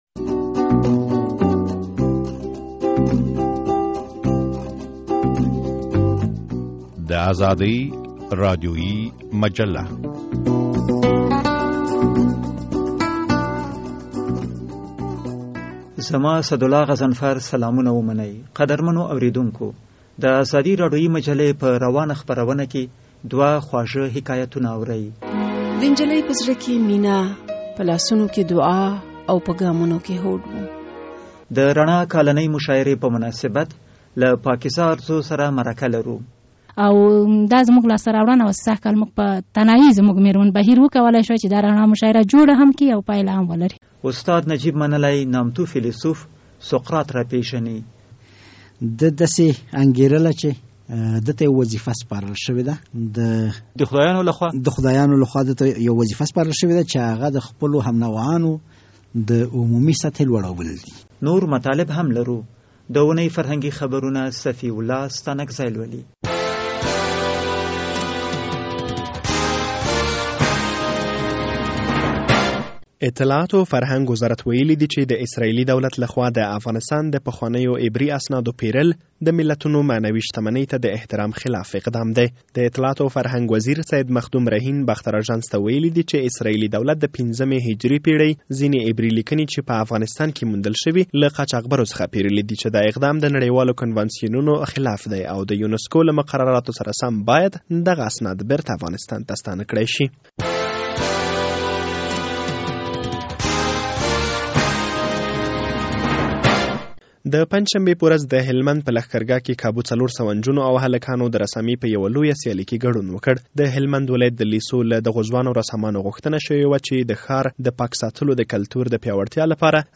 د ازادي راډیویي مجلې په دې خپروونه کې د نامتو فیلسوف سقراط د ژوند او اهمیت په باره کې مرکه اورئ.